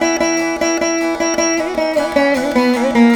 152B VEENA.wav